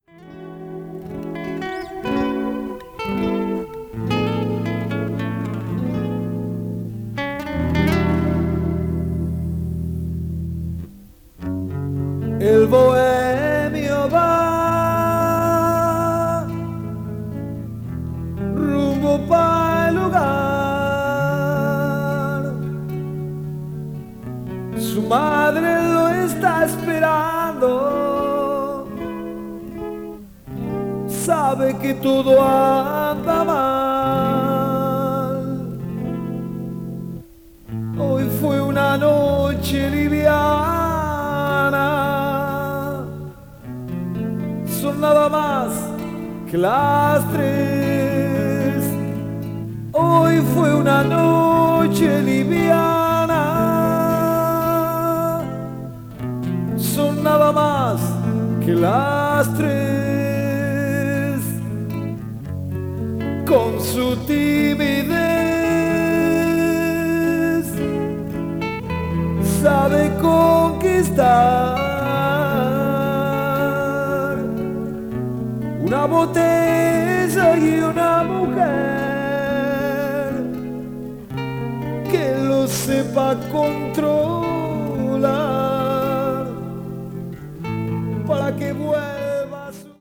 contemporary jazz   crossover   fusion   mellow groove